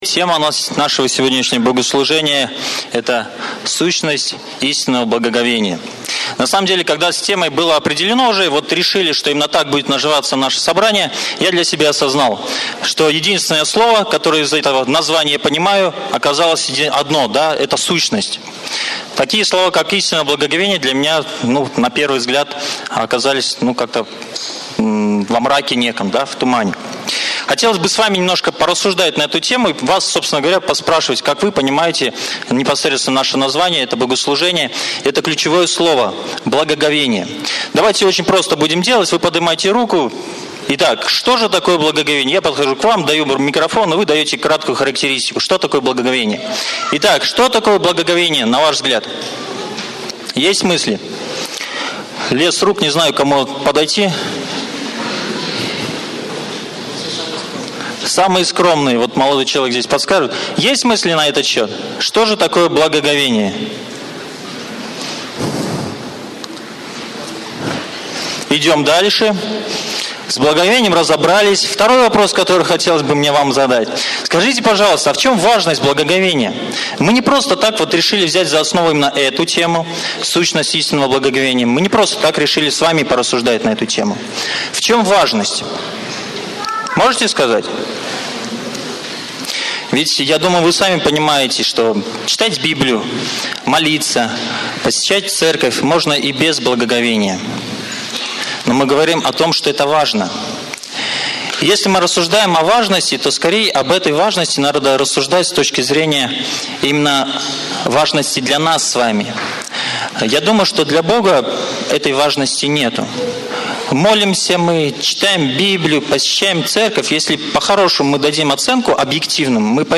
Церковь: Московская Центральная Церковь ЕХБ (Местная религиозная организация "Церковь евангельских христиан-баптистов г. Москвы")